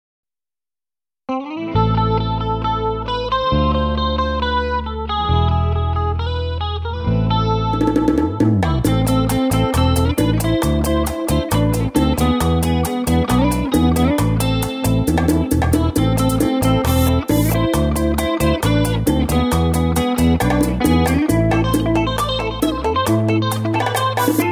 Громкие рингтоны